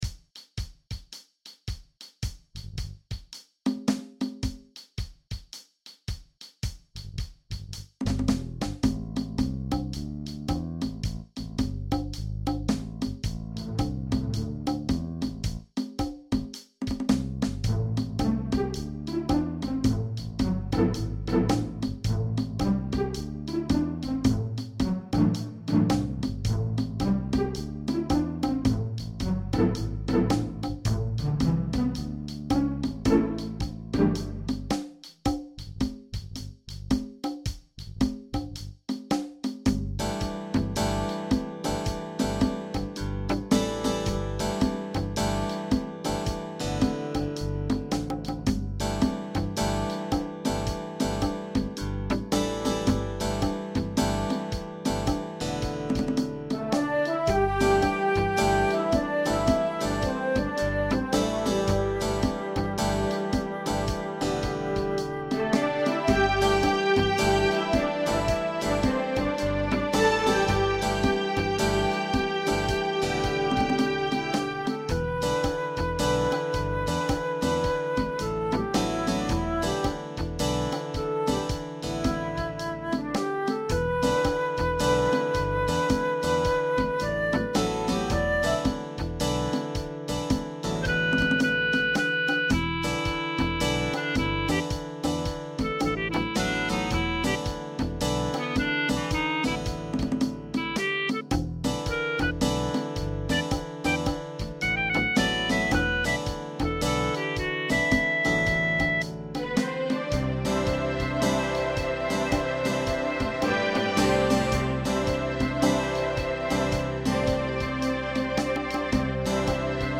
Die kompletten Percussion Off-Beats sind geklopft und aufgenommen und dann rausgehoert und in den Score geschrieben worden, und das Klarinetten Solo hab ich gepfiffen :).
Ich war (und bin) daher skeptisch vom solistischen Standpunkt - wo das Feel ja das wichtigste ist - und wenn ich es jetzt hoere find ich die Off-Beats auch etwas schwaecher wie in der Light Swing version, (an manchen Stellen einfach nicht so sinnvoll, mit einer linie die irgendwo hin fuehrt, und das ist ein Eindruck der mit weiter steigendem Swing Faktor wohl ansteigt).